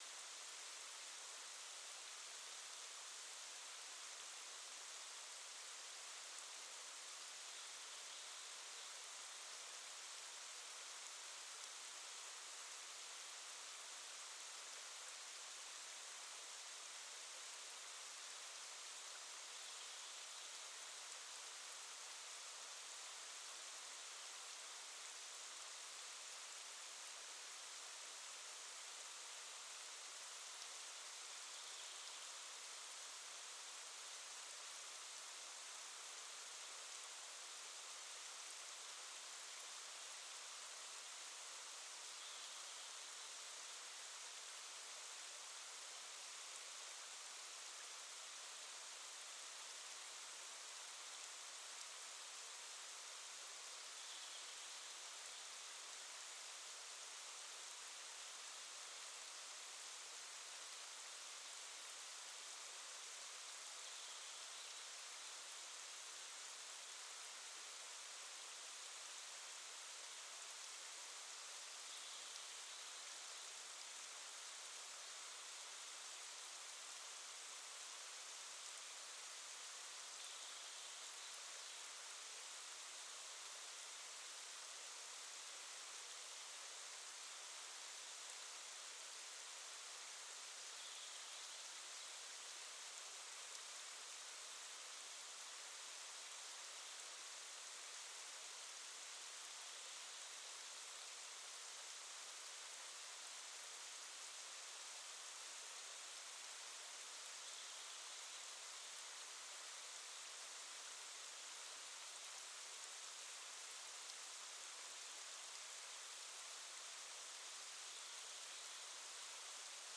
Quellrauschen8000.mp3